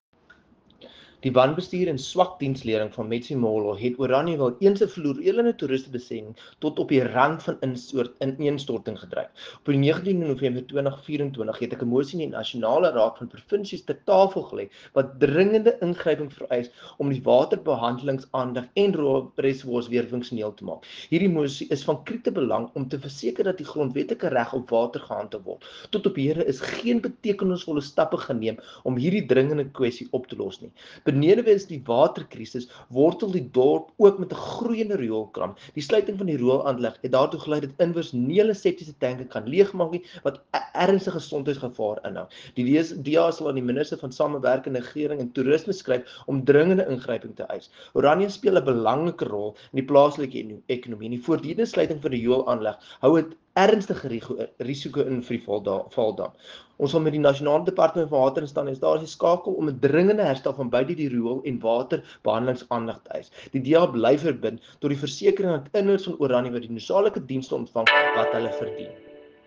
Afrikaans soundbites by Dr Igor Scheurkogel MP and
Sewerage-crisis-in-Metsimaholo-AFR.mp3